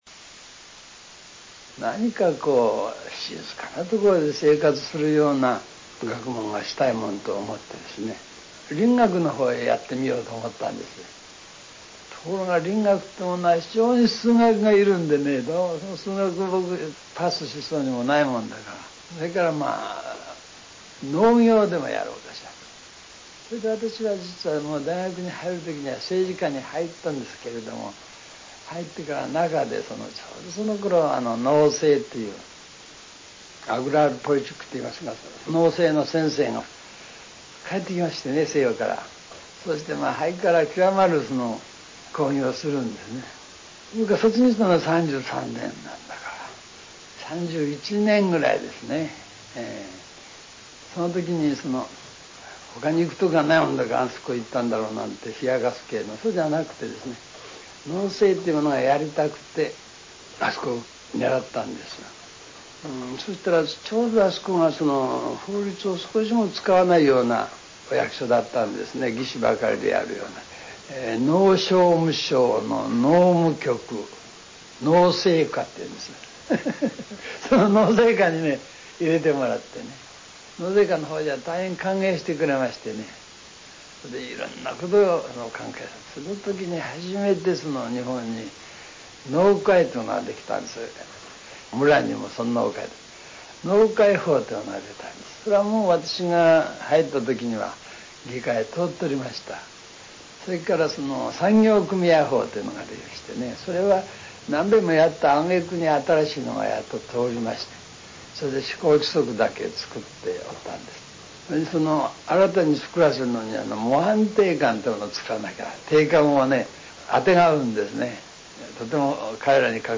柳田が明治33年（1900年）東京帝国大学法科大学を卒業後、農商務省に入省してから全国の農山村を歩くきっかけとなった話を紹介する。 以下、放送内容の中の産業組合に関係する柳田の話を録音から正確に書き起こしてみた。